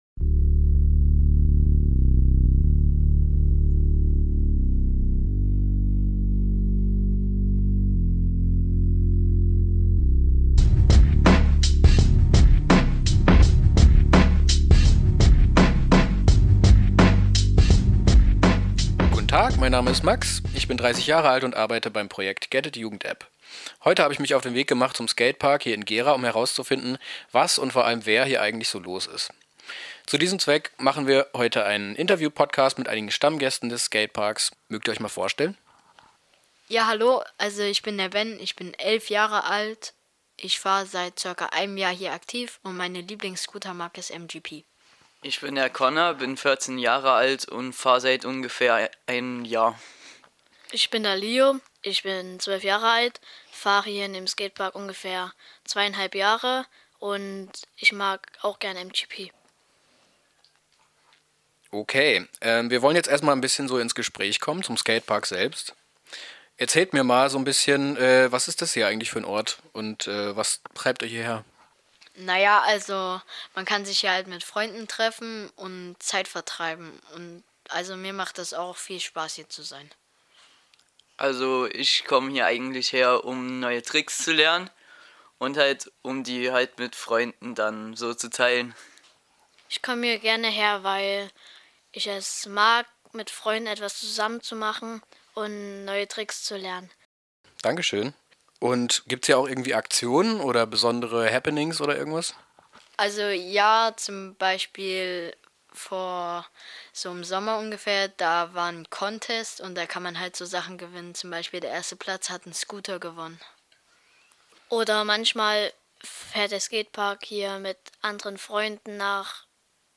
In dieser Folge sind wir zu Gast im Skatepark in Gera. Dort ist skaten nicht nur Freizeitbeschäftigung, sondern auch Lifestyle. Seid gespannt auf Buttercups, 360's und amerikanischen Rap!